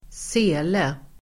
Uttal: [²s'e:le]